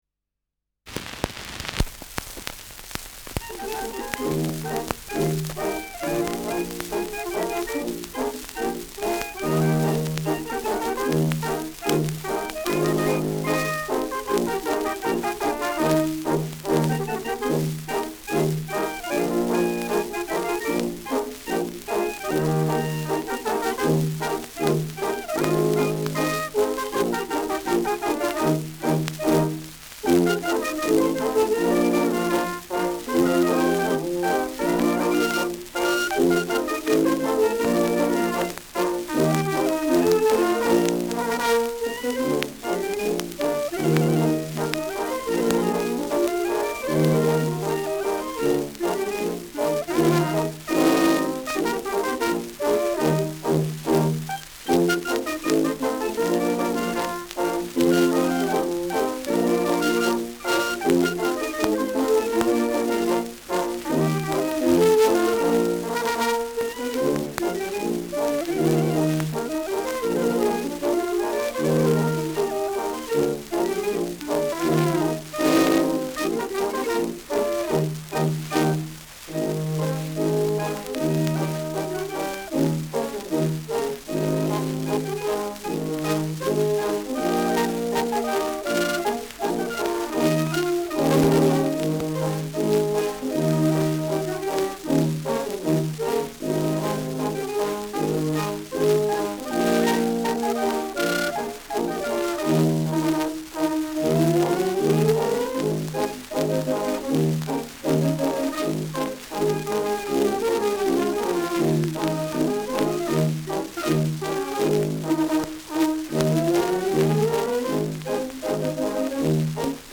Schellackplatte
Stärkeres Grundrauschen : Gelegentlich leichtes bis stärkeres Knacken : Verzerrt an lauten Stellen
Kapelle Wittelsbach, Regensburg (Interpretation)
[Nürnberg] (Aufnahmeort)